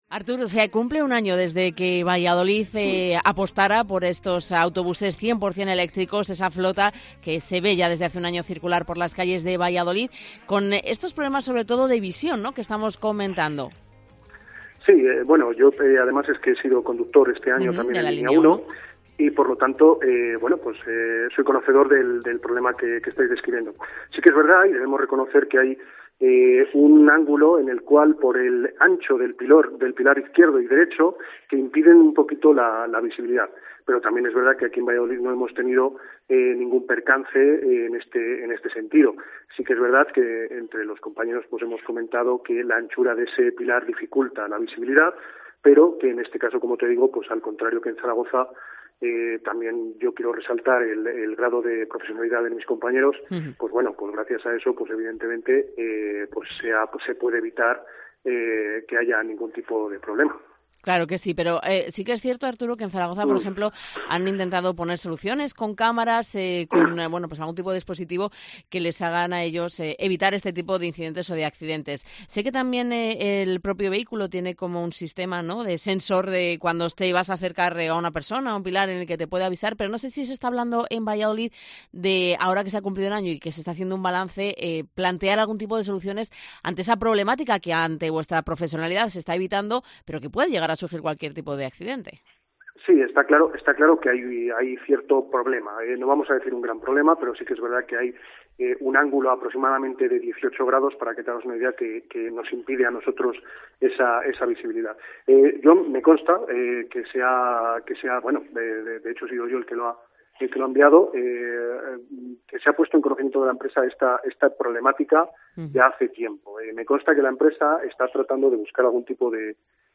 Un conductor explica en COPE el ángulo muerto de los últimos autobuses adquiridos en Valladolid